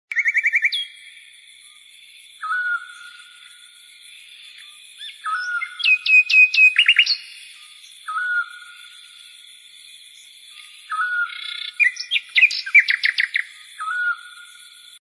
The bird is a high-energy audio clip commonly used in memes, TikToks, and YouTube shorts all sound effects sound and meme culture.
bird from all sound effects
Featuring a punchy beat with iconic "bird", it's a staple in modern all sound effects sound and meme culture.